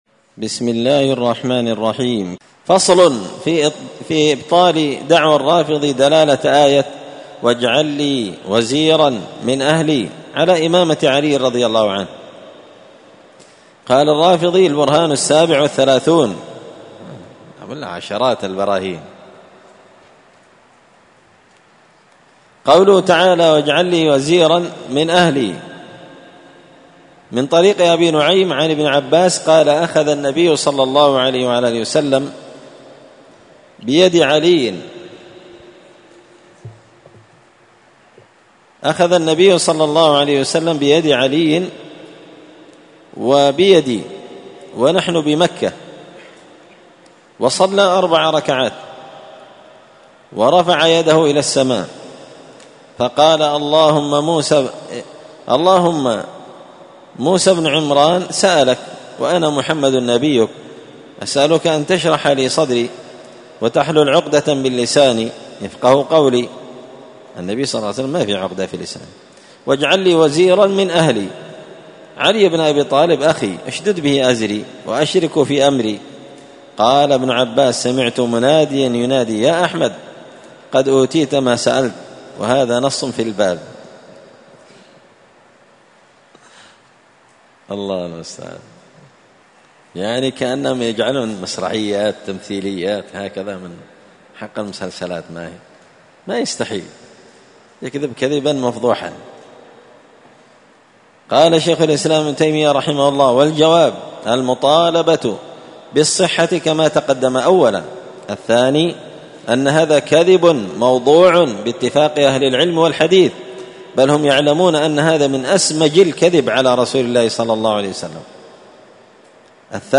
الأربعاء 14 صفر 1445 هــــ | الدروس، دروس الردود، مختصر منهاج السنة النبوية لشيخ الإسلام ابن تيمية | شارك بتعليقك | 65 المشاهدات
مسجد الفرقان قشن_المهرة_اليمن